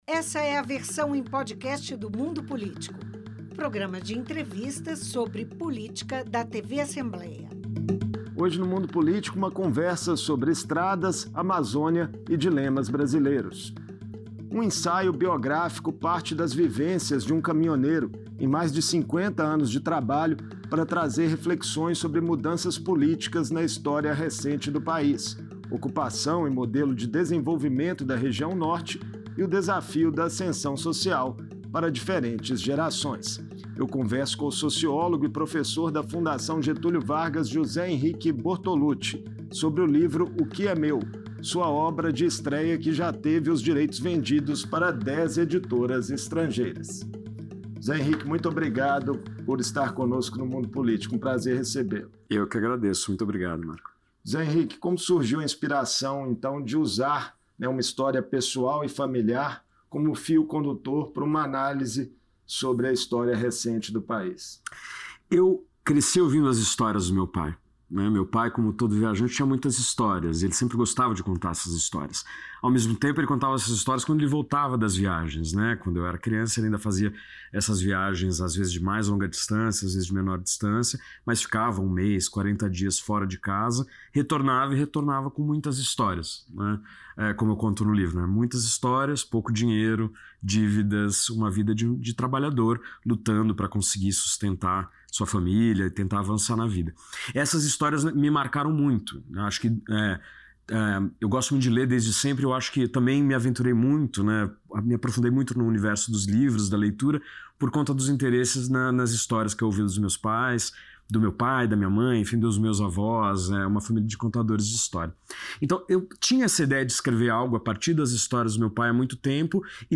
Em entrevista